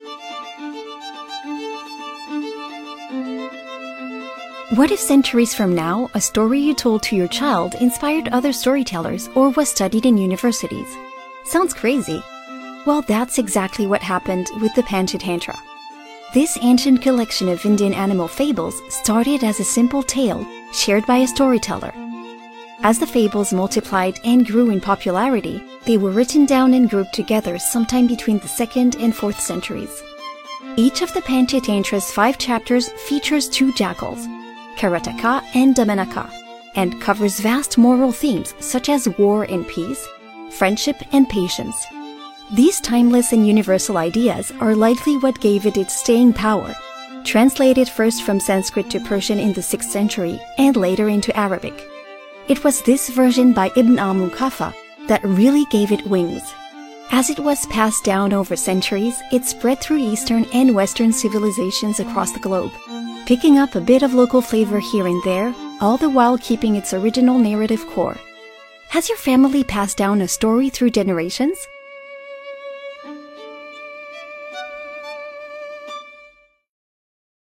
VOIX NATURELLE – NARRATION (anglais)